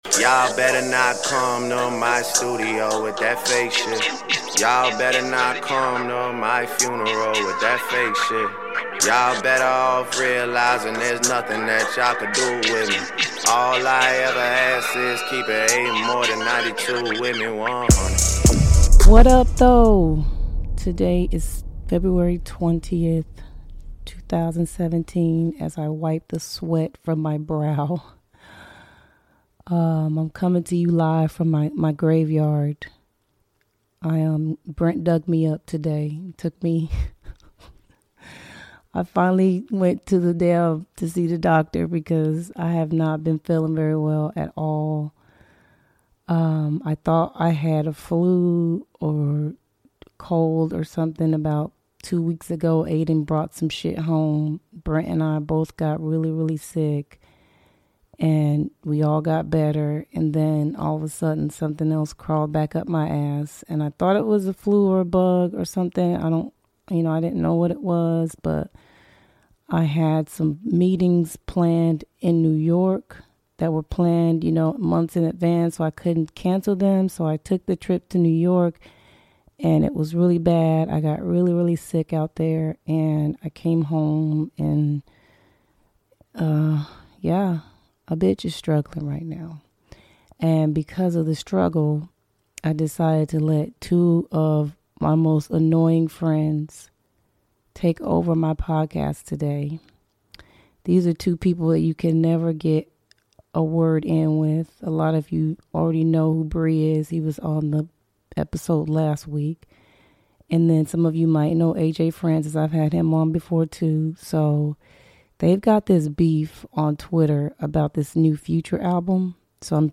Well, shit finally got real and I went to the hospital and found out I have a severe sinus infection. Not wanting to cough, sneeze and run to the bathroom every 15 minutes, I asked two of my most outspoken, opinionated friends to cover what I wouldve covered but without as much of my opinion.